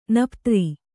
♪ naptri